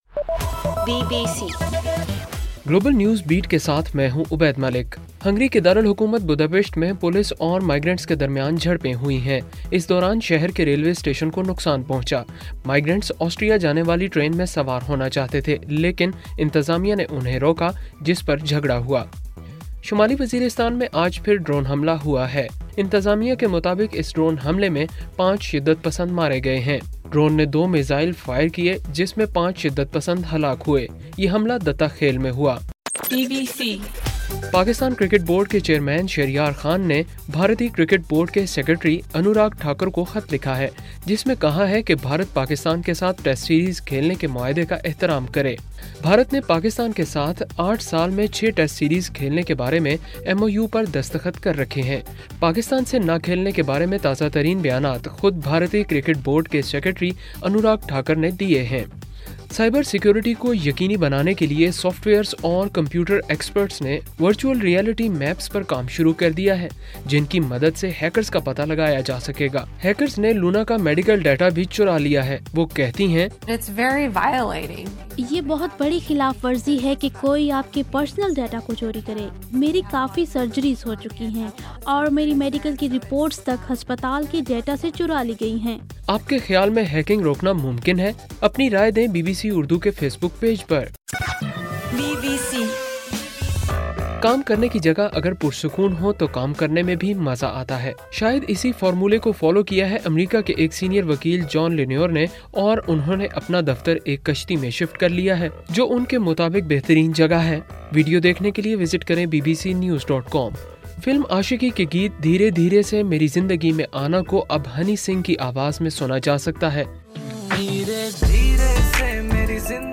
ستمبر 3: صبح 1 بجے کا گلوبل نیوز بیٹ بُلیٹن